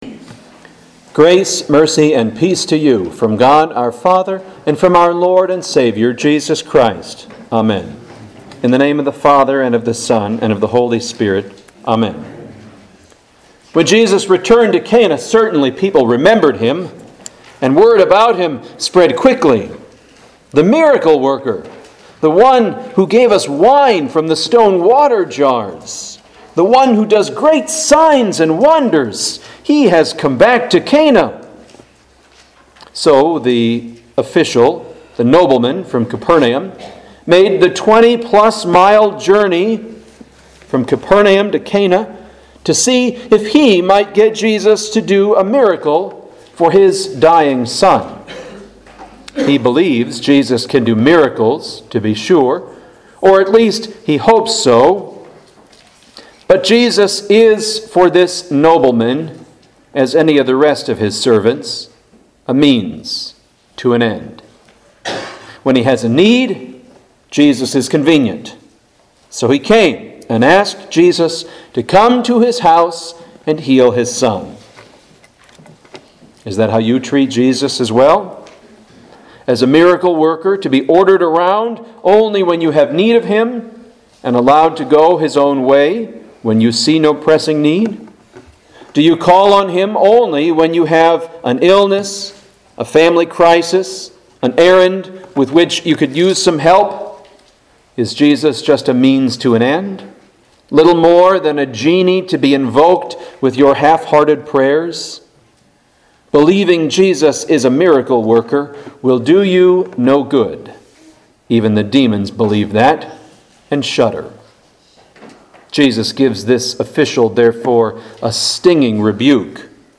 Home › Sermons › Trinity 21 Trinity 21 November 4, 2015 Trinity 21 The Twenty-First Sunday after Trinity, A  D 2015 John 4:46-54 In the name of the Father and of the  Son and of the Holy Spirit.